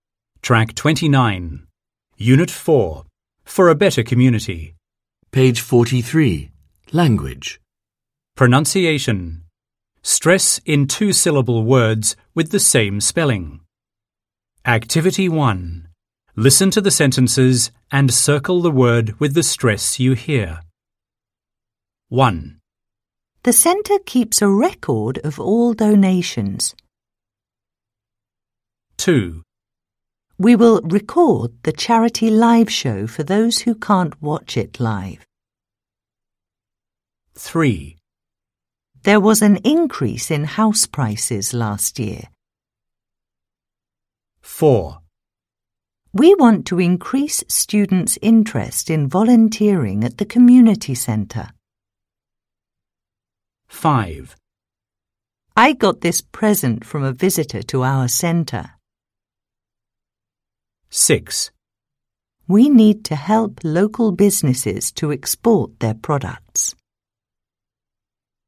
1. Listen to the sentences and circle the word with the stress you hear.